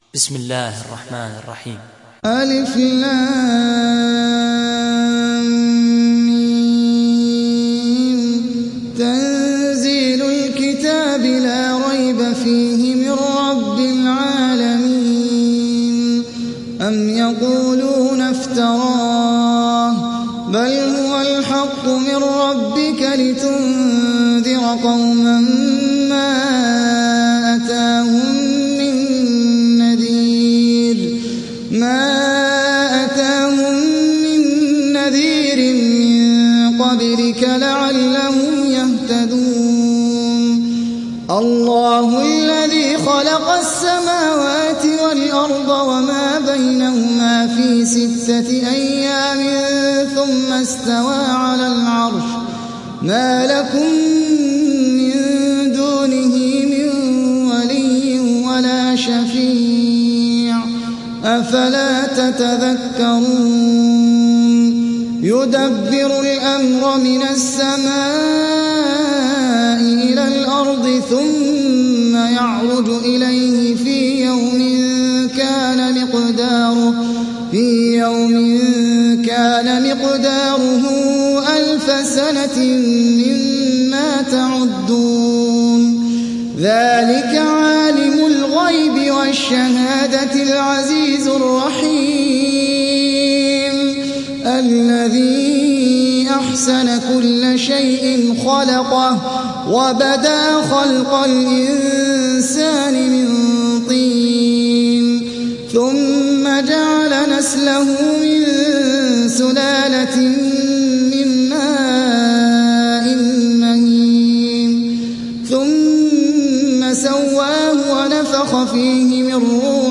Hafs an Assim